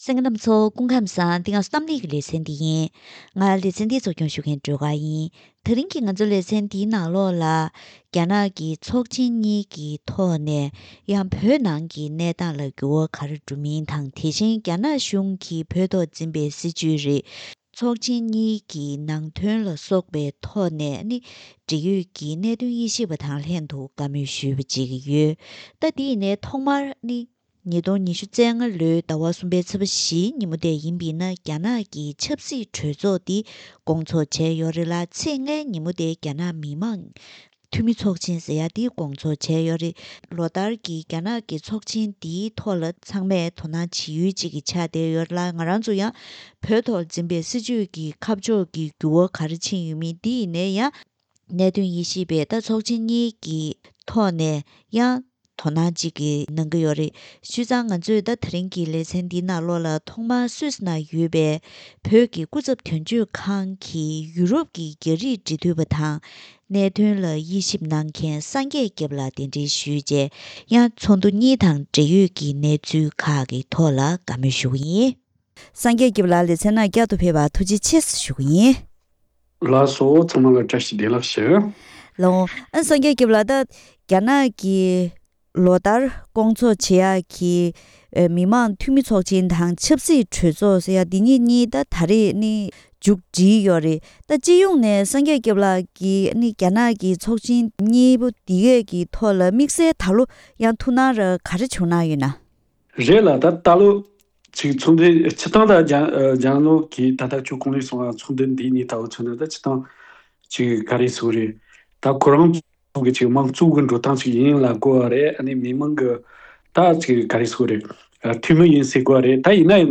ད་རིང་གི་གཏམ་གླེང་ལེ་ཚན་འདིའི་ནང་འདི་ལོའི་རྒྱ་ནག་གི་ཚོགས་ཆེན་གཉིས་ཀྱི་ཐོག་ནས་བོད་ནང་གི་གནས་སྟངས་དང་། རྒྱ་ནག་གཞུང་གིས་བོད་ཐོག་འཛིན་པའི་སྲིད་ཇུས། ཚོགས་འདུའི་འབྲེལ་ཡོད་ནང་དོན་སོགས་ཀྱི་ཐོག་གནད་དོན་དབྱེ་ཞིབ་པ་དང་ལྷན་དུ་བཀའ་མོལ་ཞུས་པ་ཞིག་གསན་རོགས་གནང་།